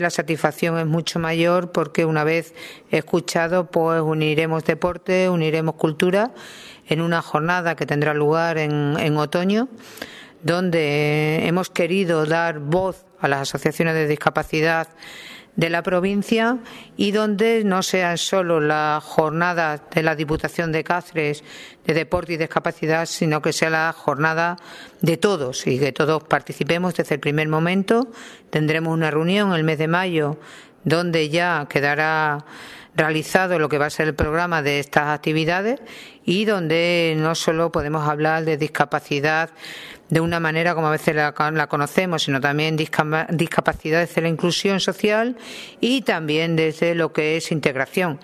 CORTES DE VOZ